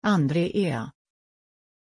Pronuncia di Andreea
pronunciation-andreea-sv.mp3